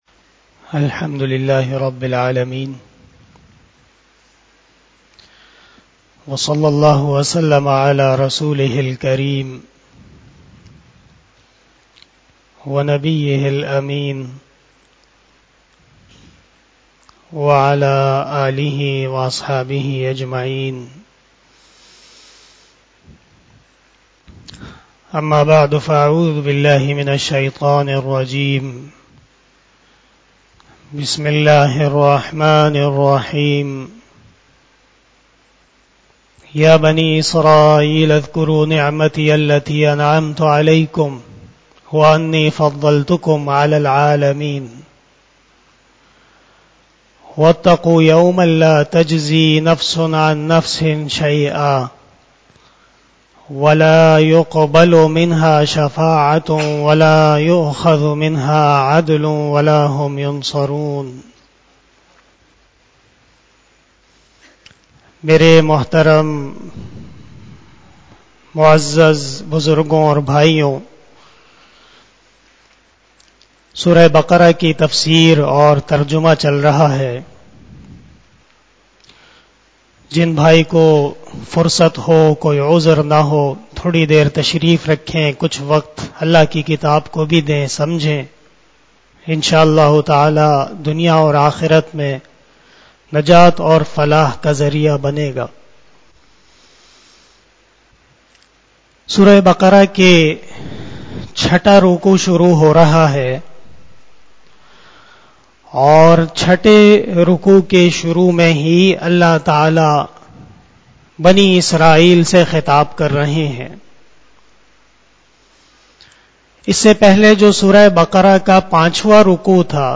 بیان شب جمعۃ المبارک